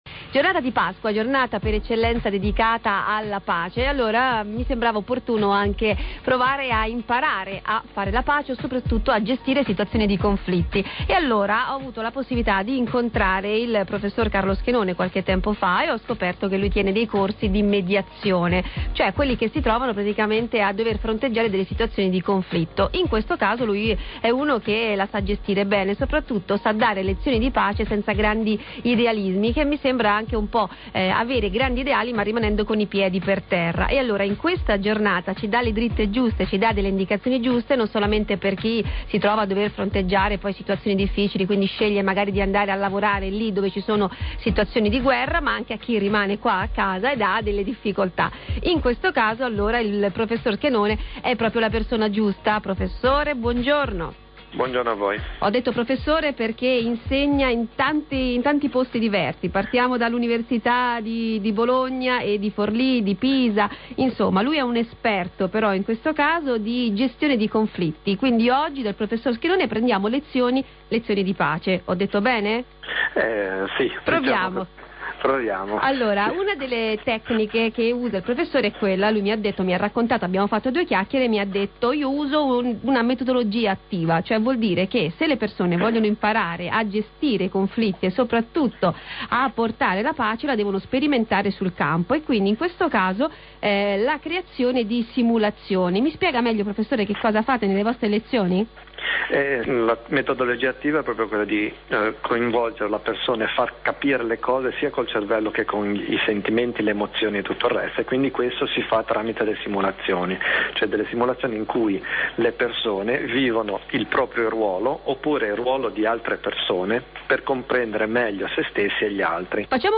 Intervista del 23 marzo 2008 a Radio Capital